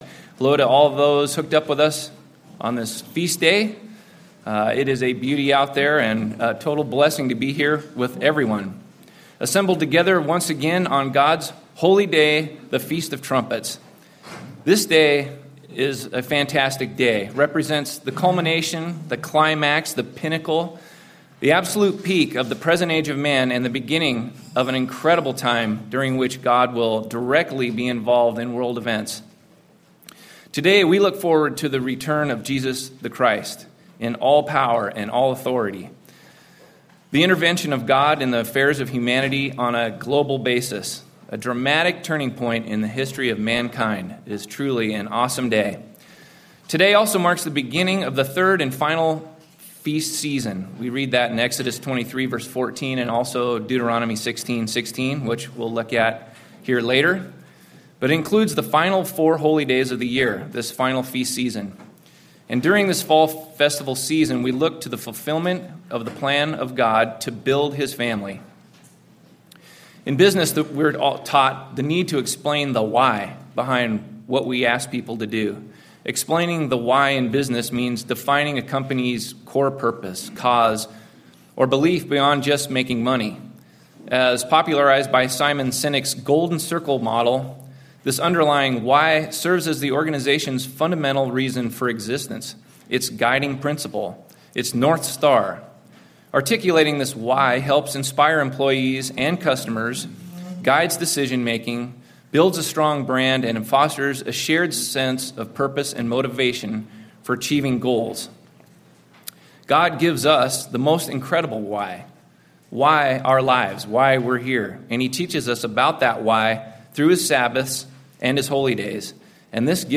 This Includes an offertory message.